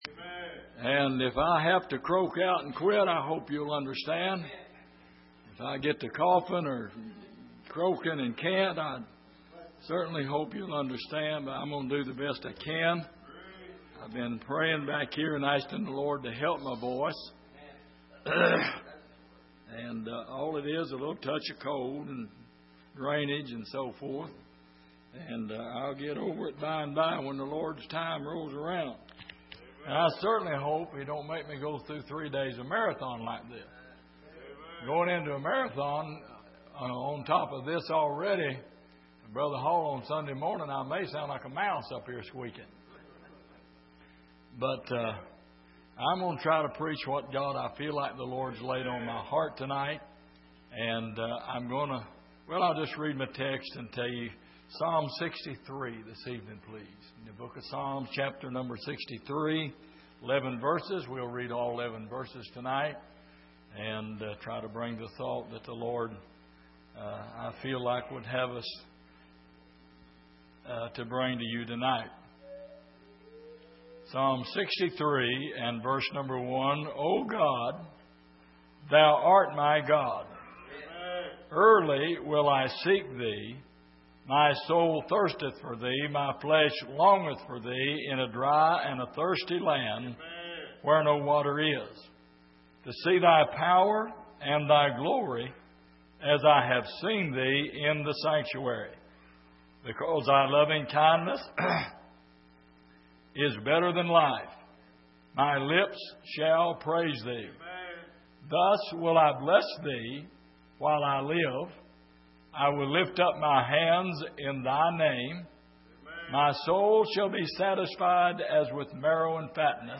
Passage: Psalm 63:1-11 Service: Sunday Evening